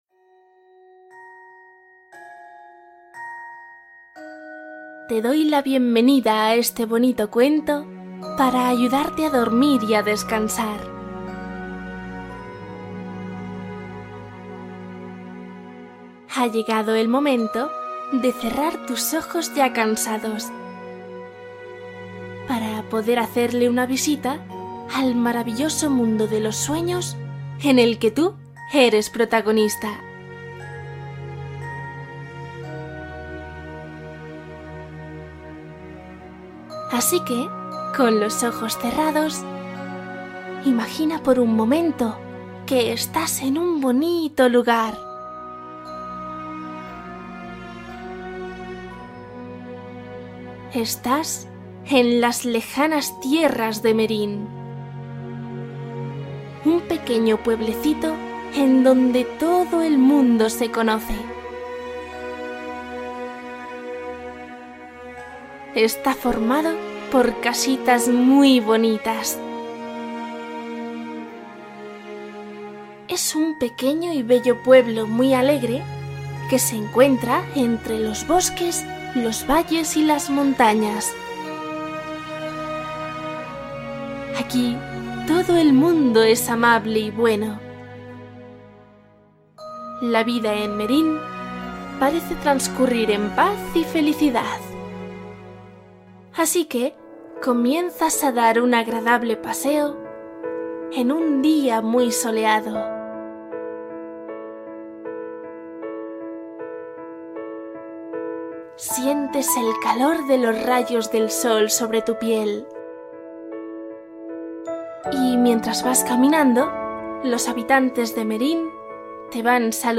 Cuento especial para dormir niños: historias para soñar bonito